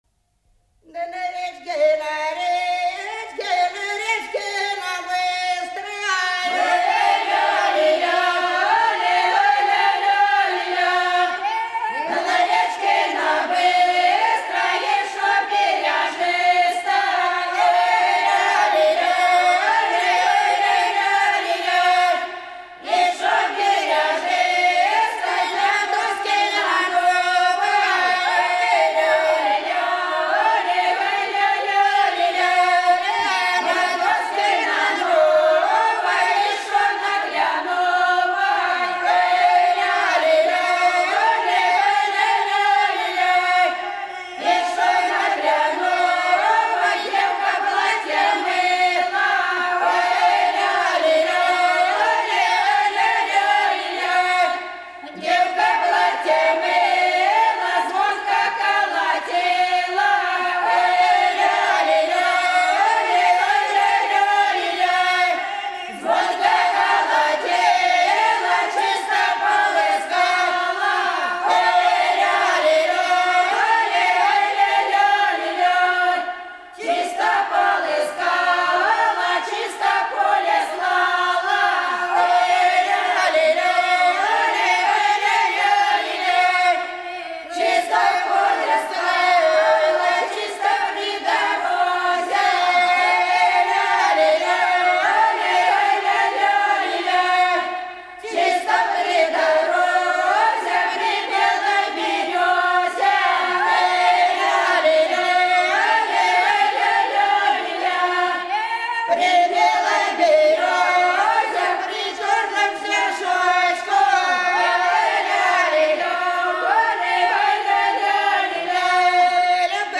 Голоса уходящего века (Курское село Илёк) Да на речке, на речке (таночная, в весенний пост)